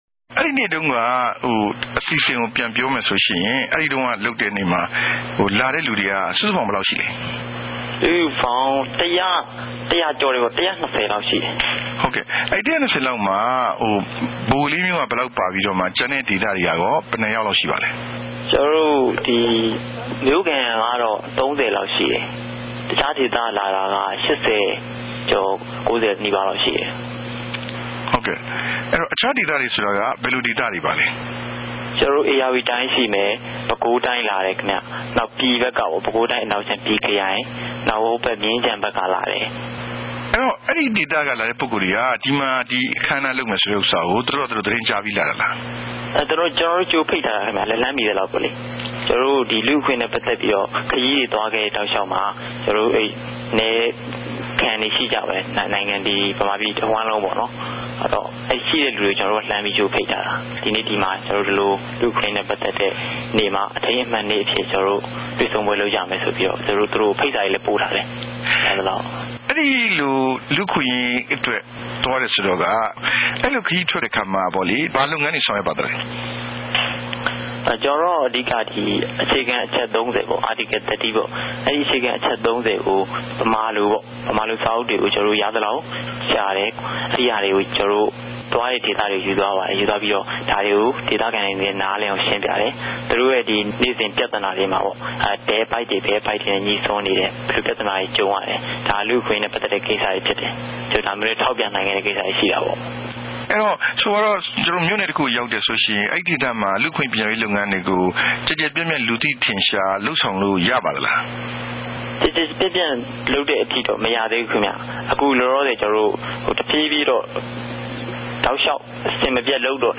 ူမန်မာ့ပထမဆုံး လူႛအခြင့်အရေးနေႛ ကဵင်းပိံိုင်အောင် ဋ္ဌကိြးစားခဲ့သူတဦးိံြင့် အမေးအေူဖ